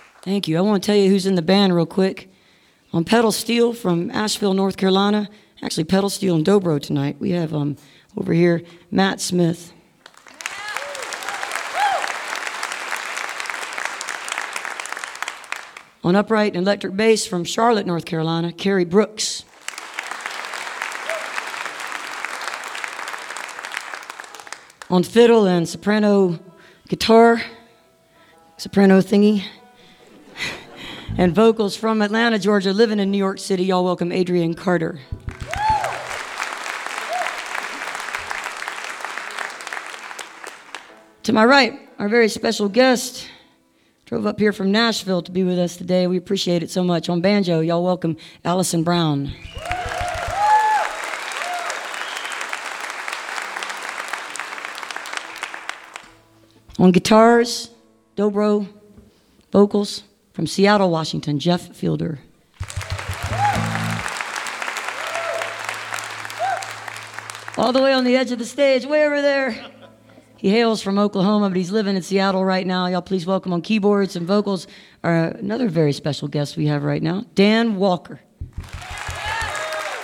(captured from a websteam)
04. band introductions (1:20)